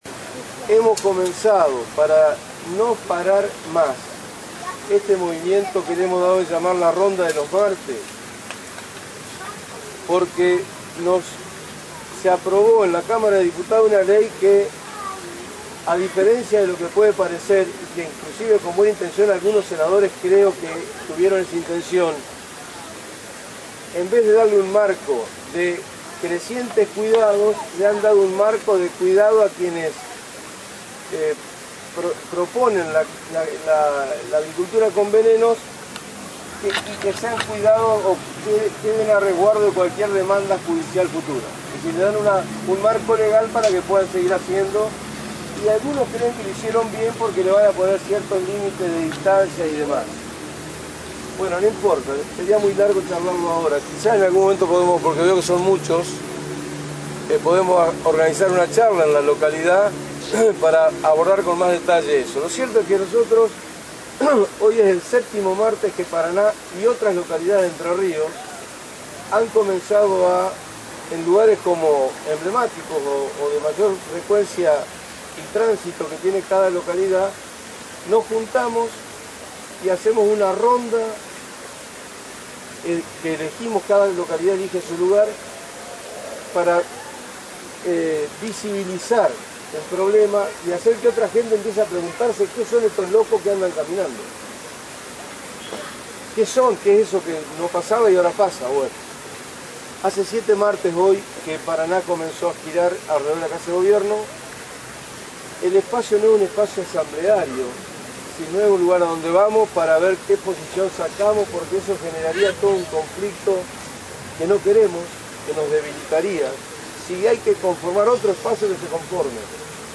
La novedosa modalidad de protesta pacífica denominada «ronda de los martes», hoy moviliza a ciudadanos de unas 15 ciudades entrerrianas.